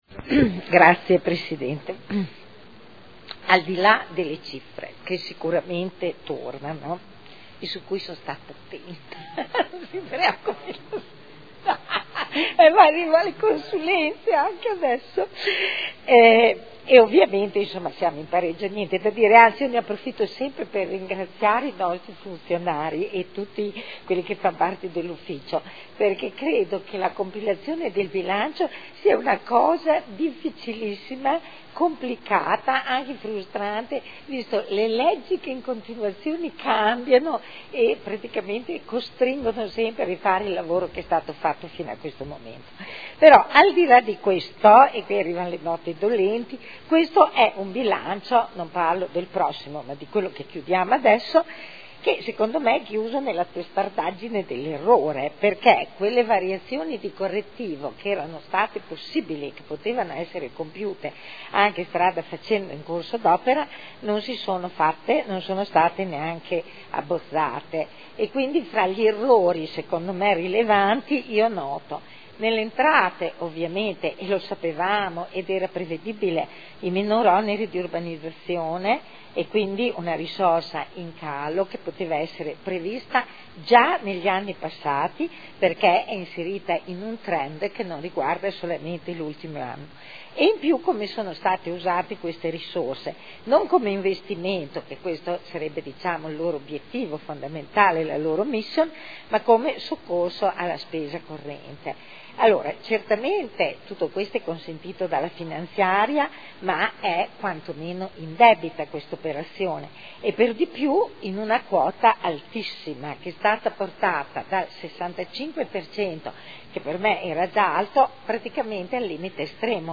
Dibattito su proposta di deliberazione: rendiconto della gestione del Comune di Modena per l’esercizio 2011 – Approvazione